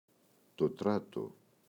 τράτο, το [‘trato]